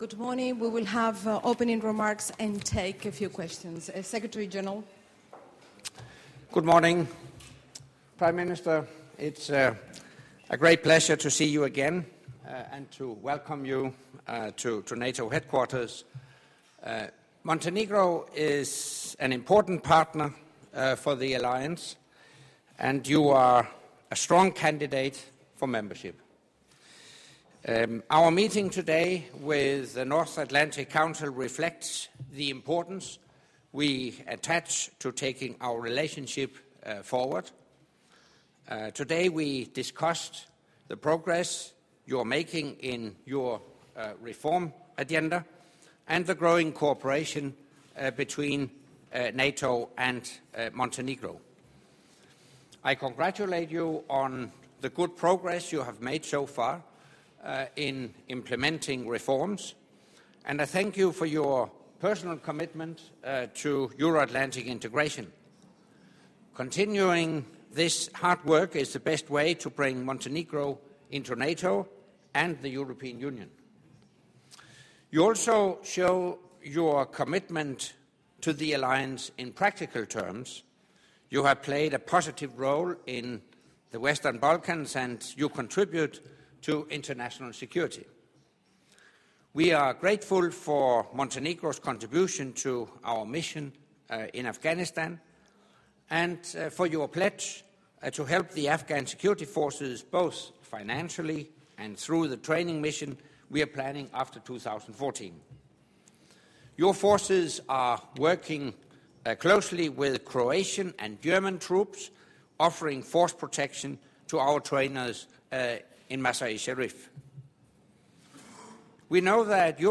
Joint press point with NATO Secretary General Anders Fogh Rasmussen and the Prime Minister of Montenegro, Milo Đjukanović